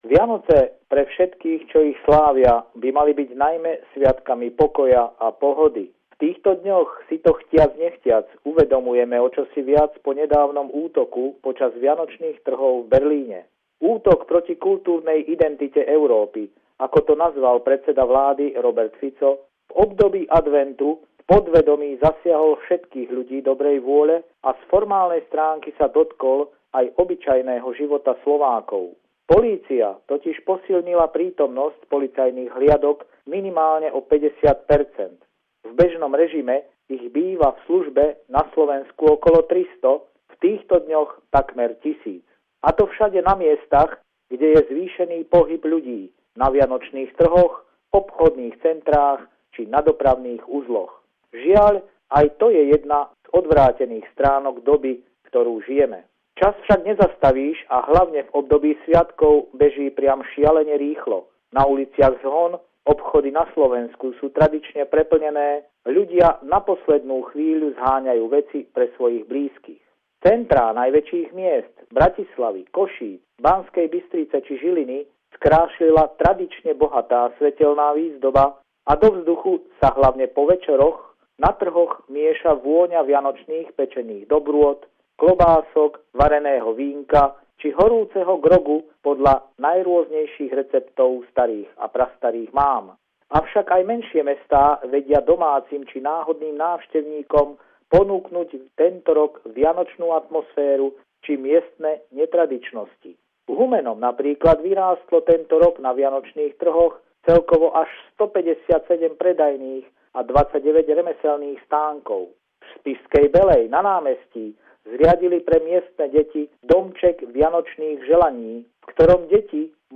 Vianočná zvuková mozaika od našich kolegov a spolupracovníkov z rôznych kútov sveta